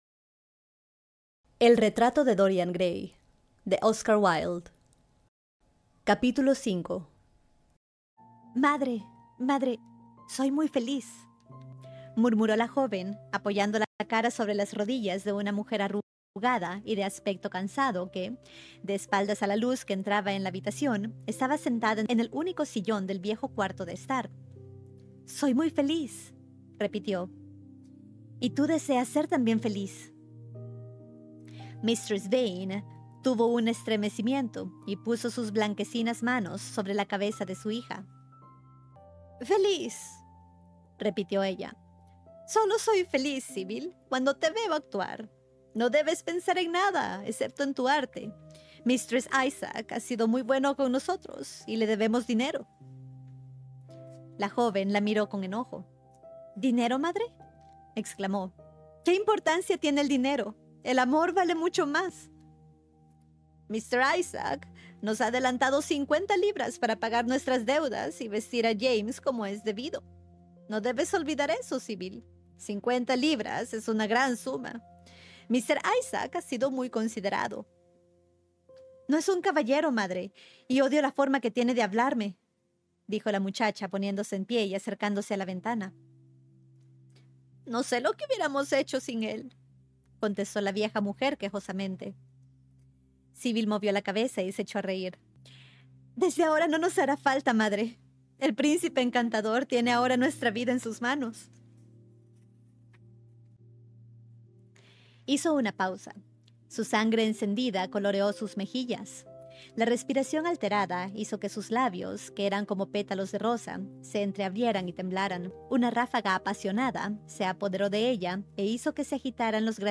En este episodio de Clásicos al Oído, cerramos la lectura de El Gran Gatsby de F. Scott Fitzgerald, extraída directamente de nuestras sesiones en vivo en Twitch.✨ En este último capítulo, Nick enfrenta las consecuencias de la tragedia y ve cómo el mundo que Gatsby construyó con tanto esfuerzo se desmorona en el olvido.